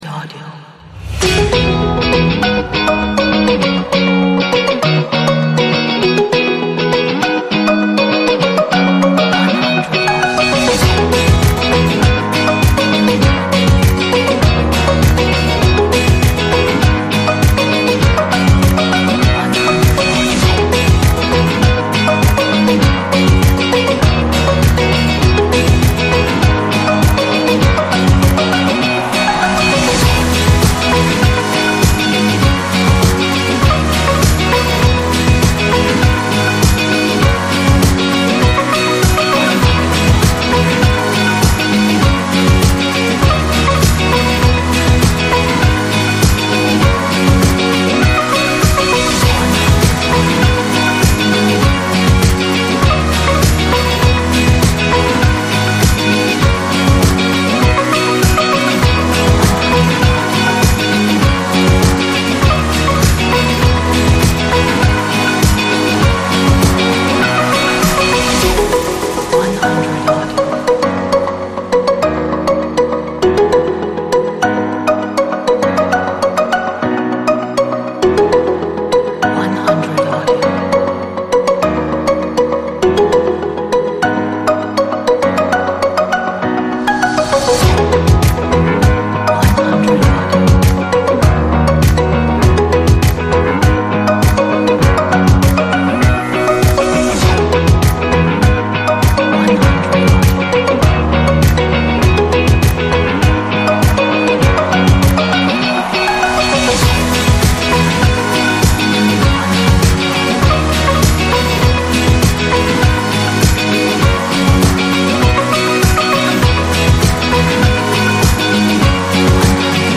这是一首鼓舞人心的背景音乐，充满活力和快乐的心情。